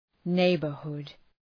Προφορά
{‘neıbər,hʋd}
neighbourhood.mp3